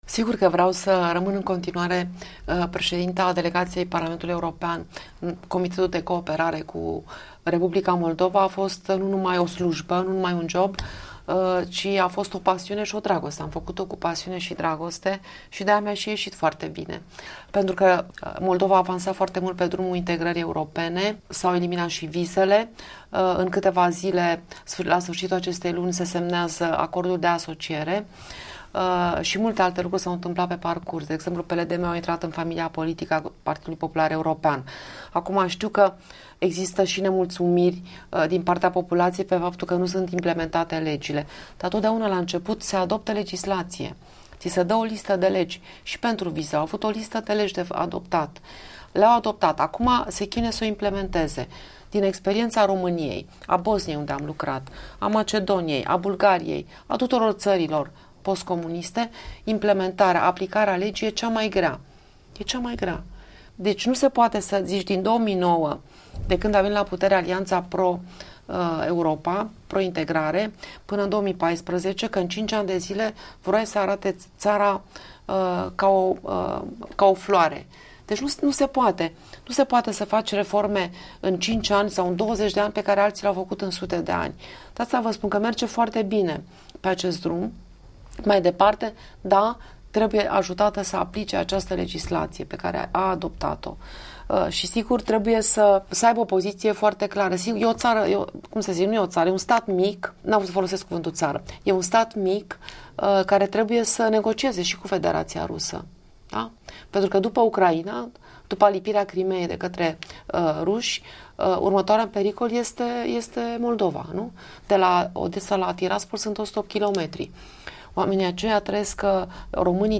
Interviurile EL: cu Monica Macovei la Praga